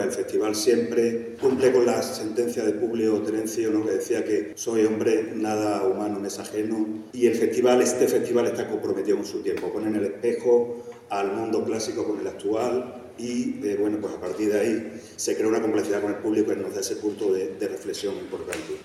Vélez participó en la presentación de la canción "Las Troyanas", basada en la obra clásica de Eurípides, la cual será representada en el Festival Internacional de Teatro Clásico de Mérida.
Escucha la intervención de Antonio Vélez en el siguiente enlace: